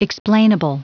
Prononciation du mot explainable en anglais (fichier audio)
Prononciation du mot : explainable